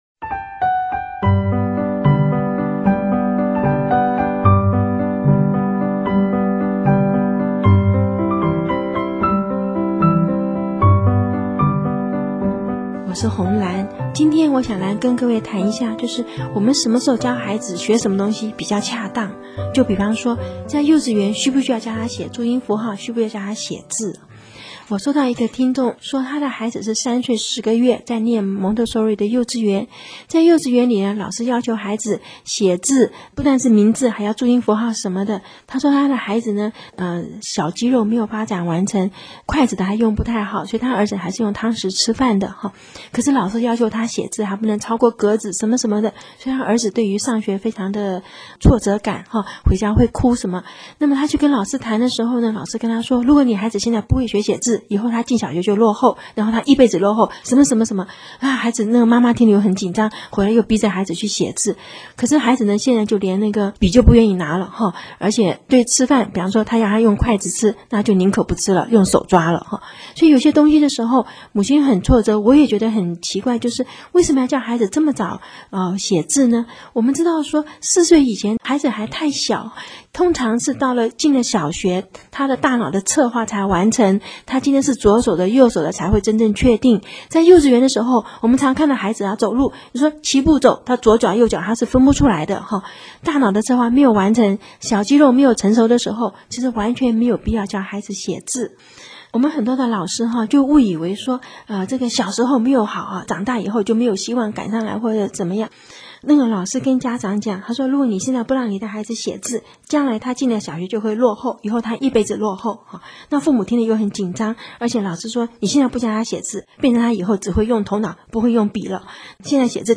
有聲書第一輯